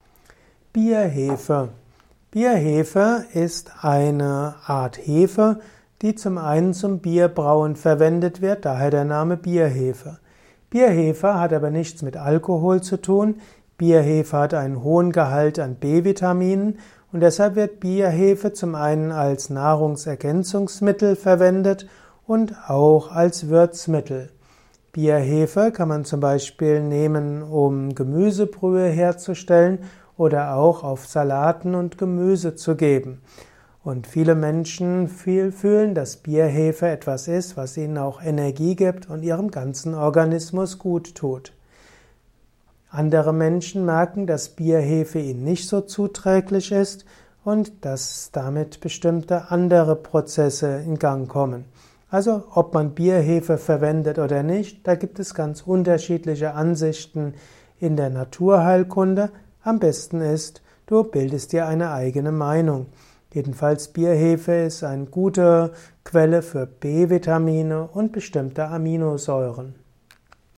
Kompakte Informationen zum Thema Bierhefe in diesem Kurzvortrag